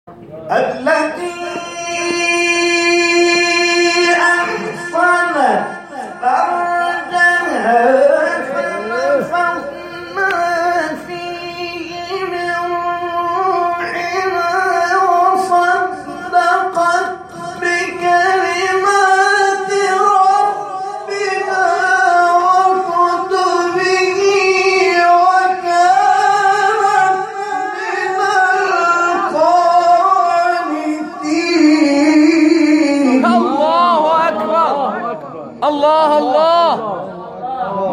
شبکه اجتماعی: مقاطع صوتی از قاریان ممتاز کشور را می‌شنوید.